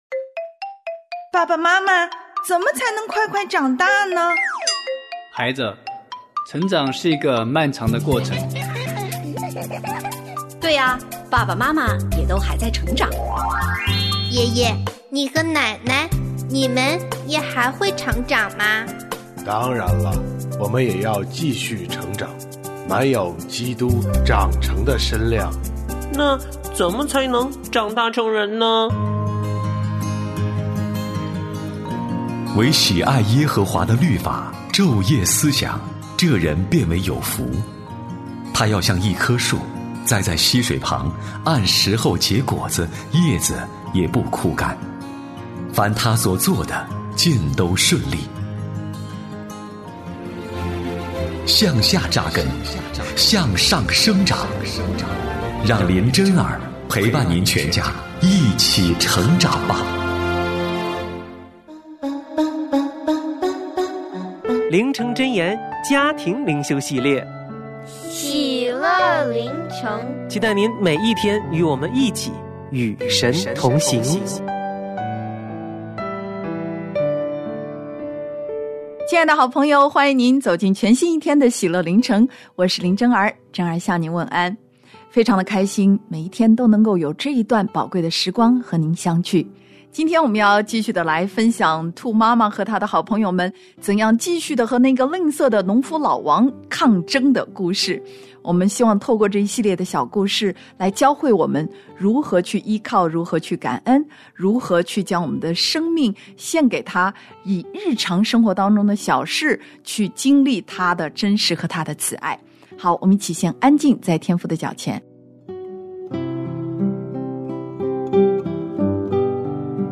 我家剧场：圣经广播剧（139）犹大王约沙法；以利亚对亚哈谢发预言